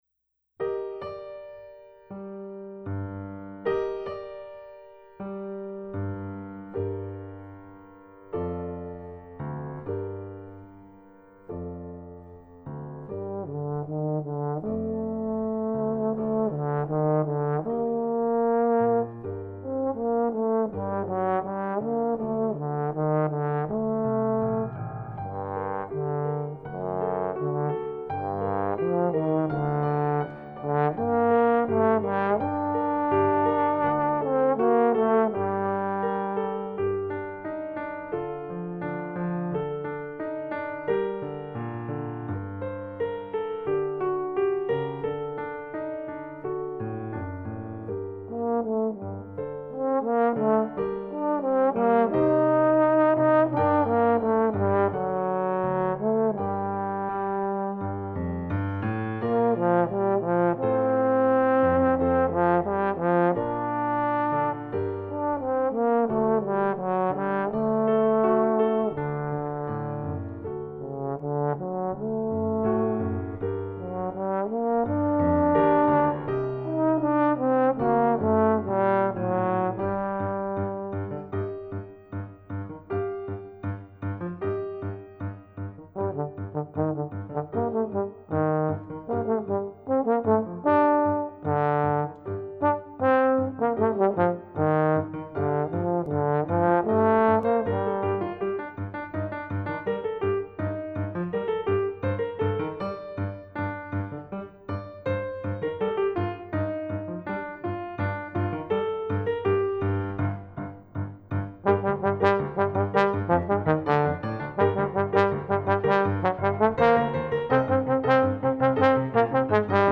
Singing – Driving – Dancing [4:00]